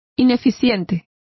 Complete with pronunciation of the translation of inefficient.